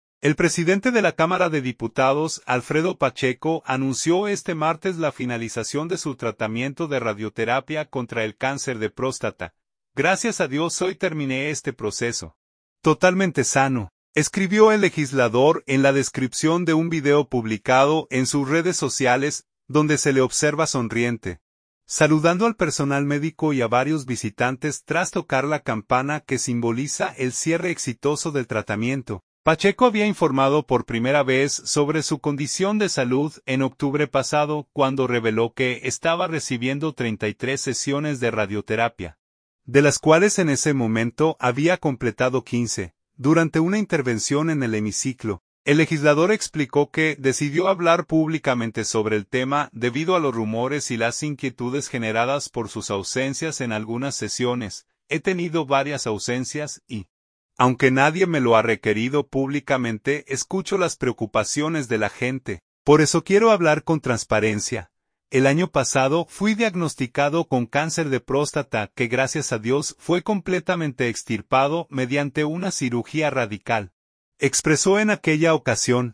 Gracias a Dios hoy terminé este proceso, totalmente sano”, escribió el legislador en la descripción de un video publicado en sus redes sociales, donde se le observa sonriente, saludando al personal médico y a varios visitantes tras tocar la campana que simboliza el cierre exitoso del tratamiento.